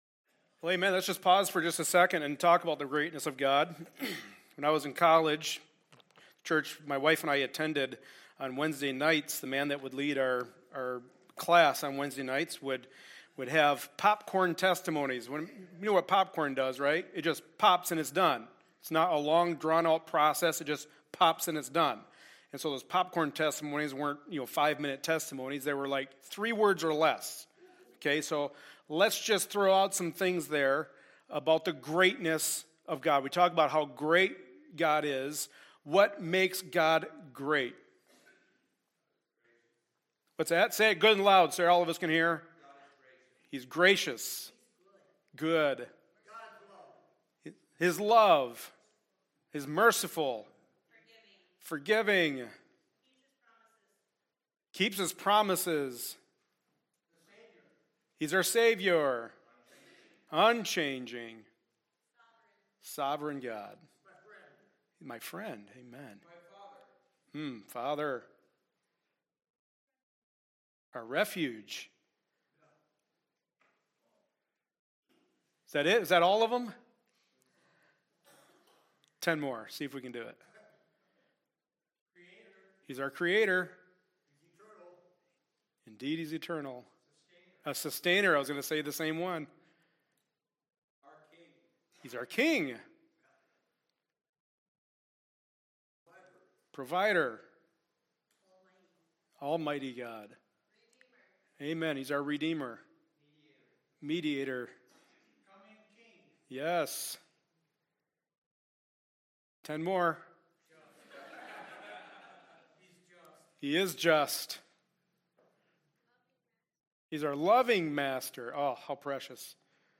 2 Timothy 2:16-26 Service Type: Sunday Morning Service A study in the Pastoral Epistles.